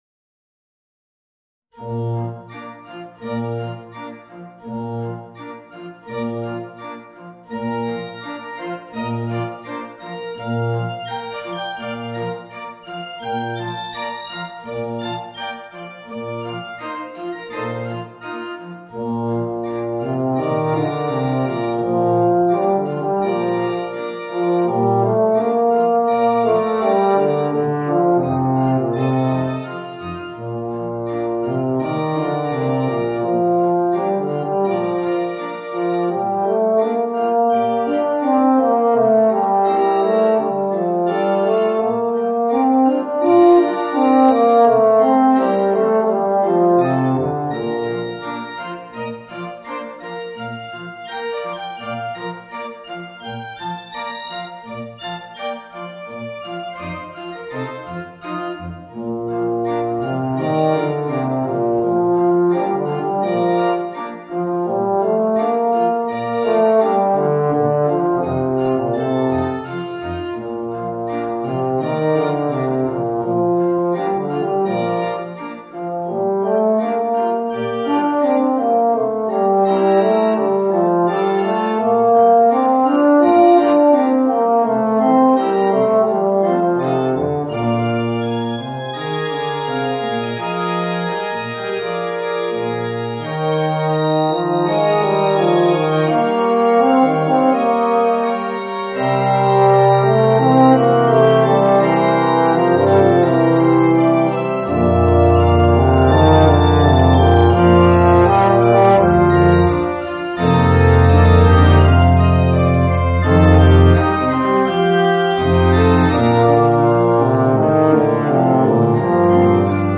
Voicing: Euphonium and Piano